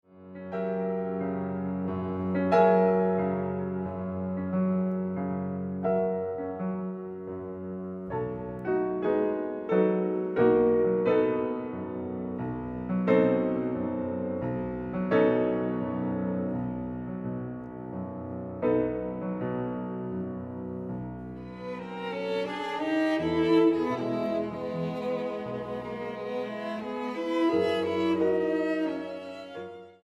Grabado del 2 al 6 de Septiembre de 2013, Sala Xochipilli
Piano: Bechstein